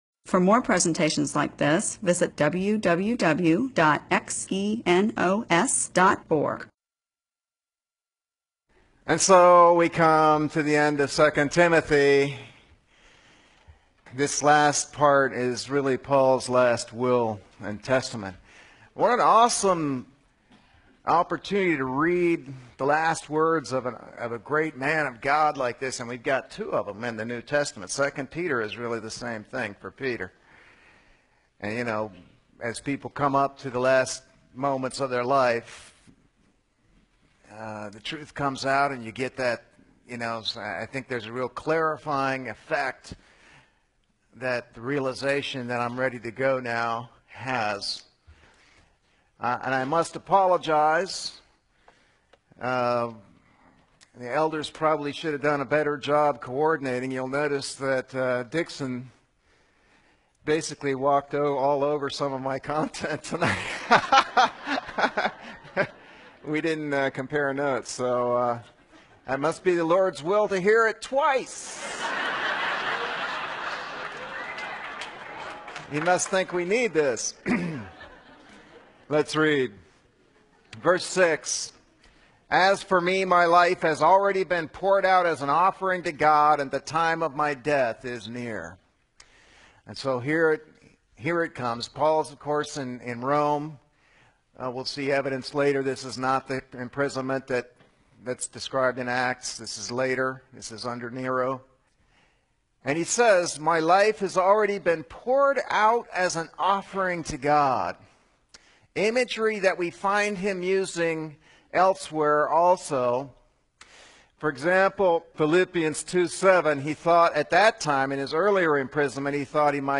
MP4/M4A audio recording of a Bible teaching/sermon/presentation about 2 Timothy 4:6-18.